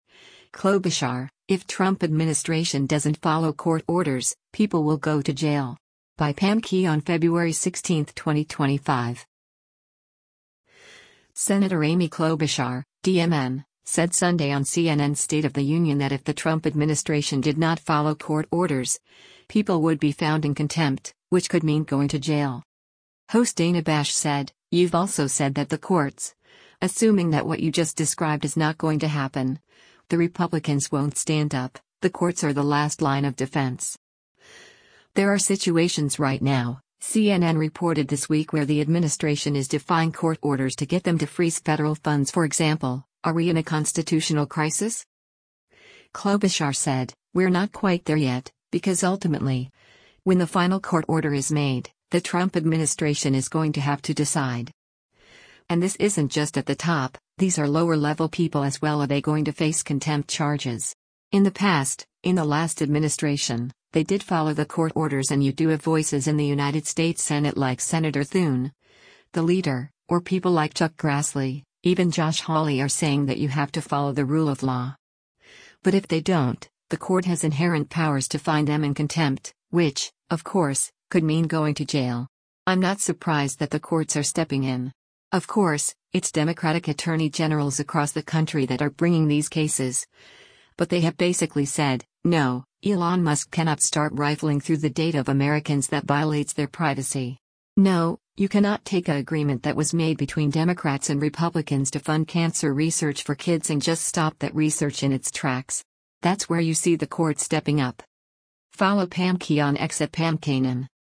Senator Amy Klobuchar (D-MN) said Sunday on CNN’s “State of the Union” that if the Trump administration did not follow court orders, people would be found in contempt, which “could mean going to jail.”